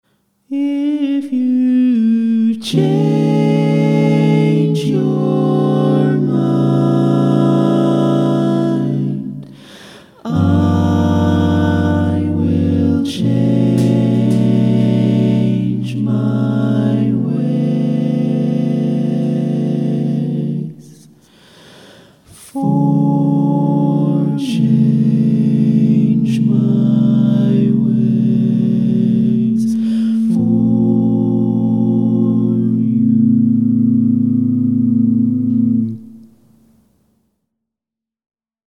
Key written in: A Major
How many parts: 4
Type: Barbershop
All Parts mix:
Learning tracks sung by